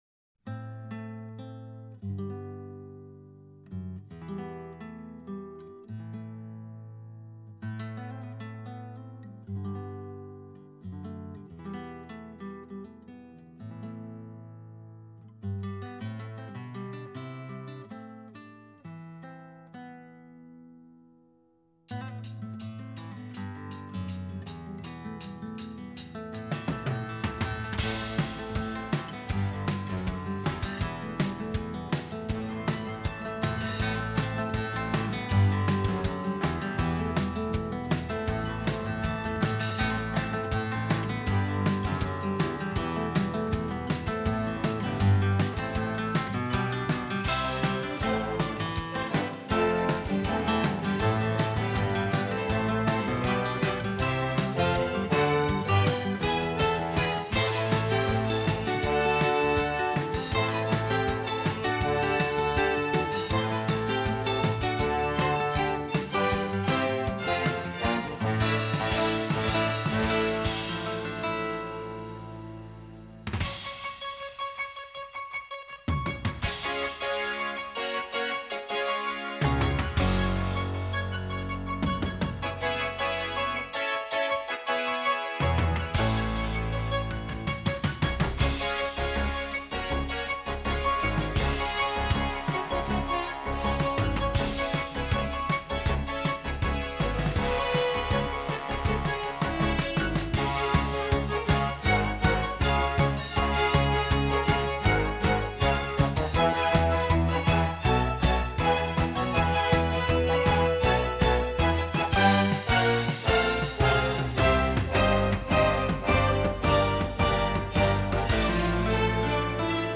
Classical, Easy Listening, New Age
instrumental musical piece
吉他曼妙哦